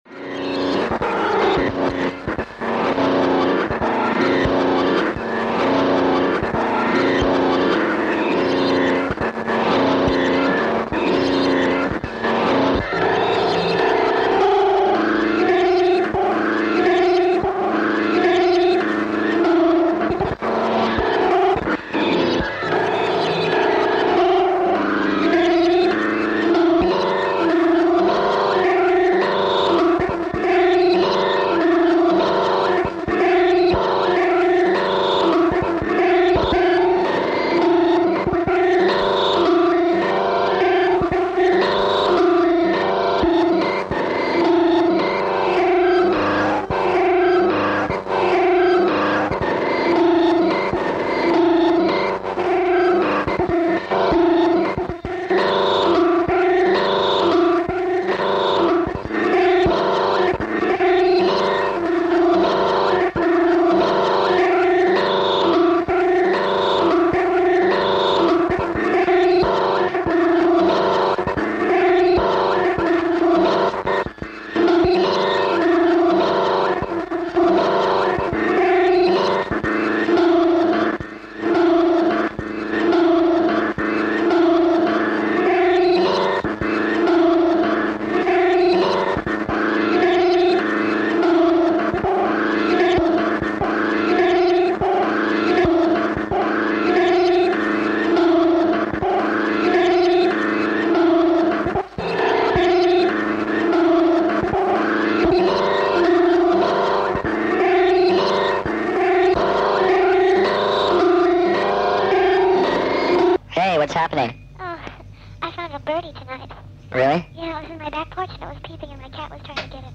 was recorded at Mectpyo Studio, Milano, in April 1980.
early experiment.